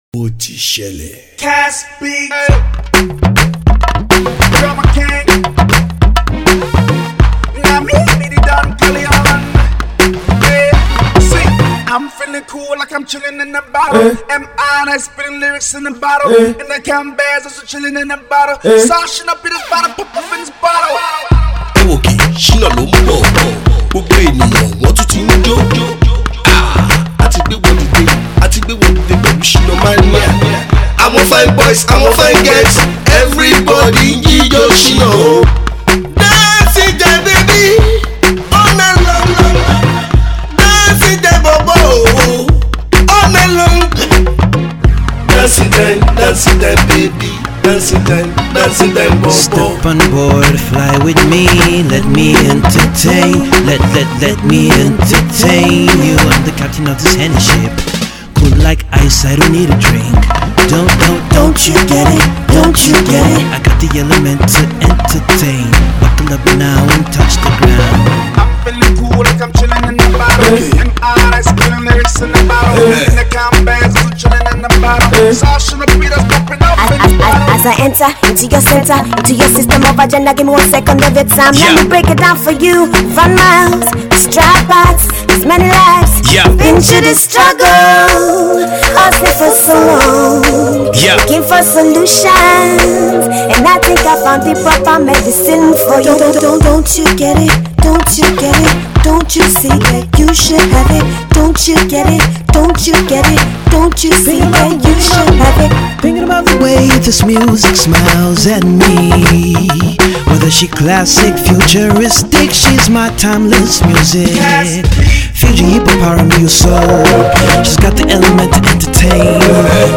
soul-music singer
alternative soul guitarist